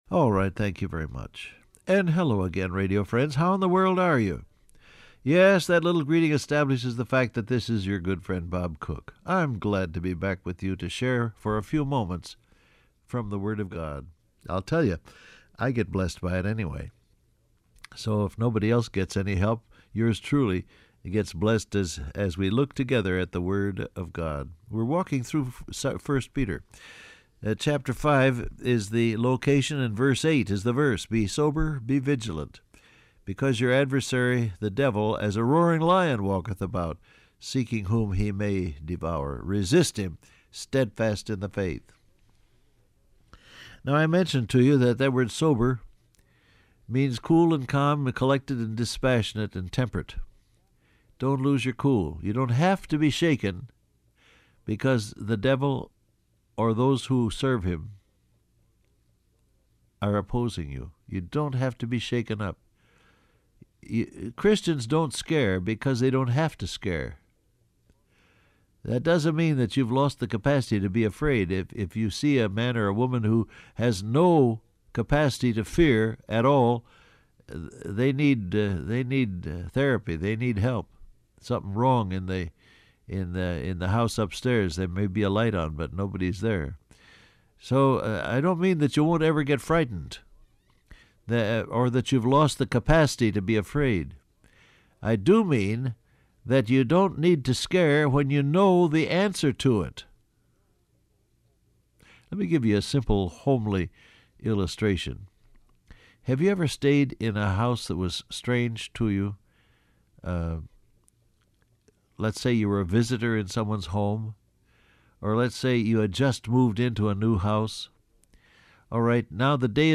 Download Audio Print Broadcast #7190 Scripture: 1 Peter 5:8 , Joshua 1:8 Topics: Fear , Lies , Devil , Alert , Mindful , Awake Transcript Facebook Twitter WhatsApp Alright, thank you very much.